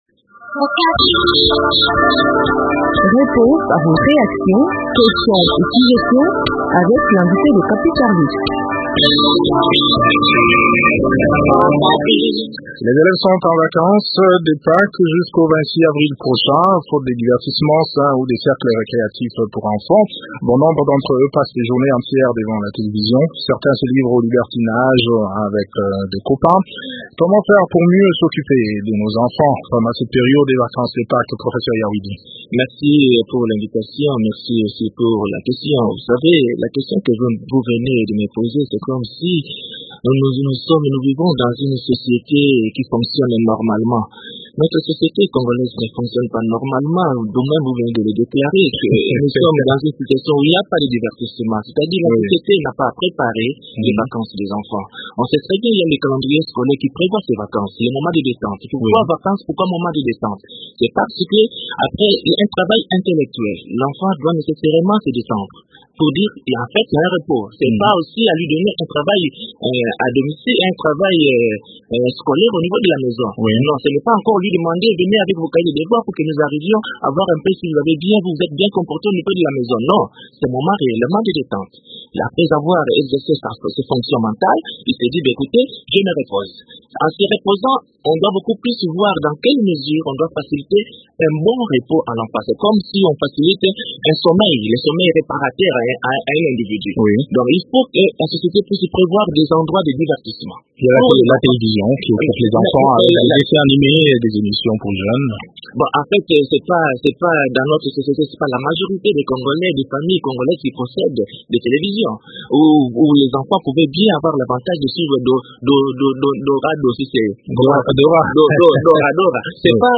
expert en éducation.